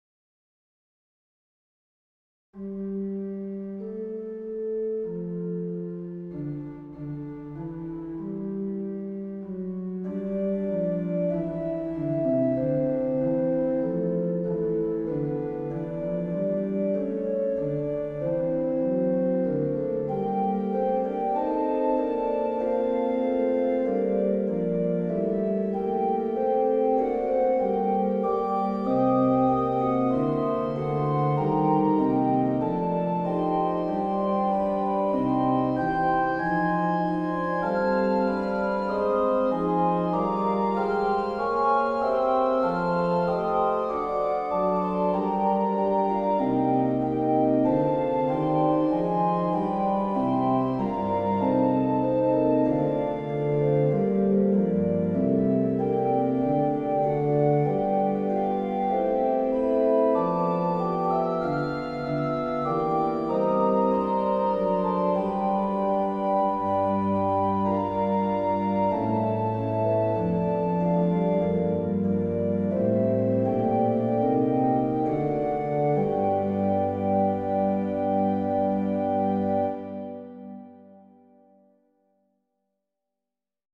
church organ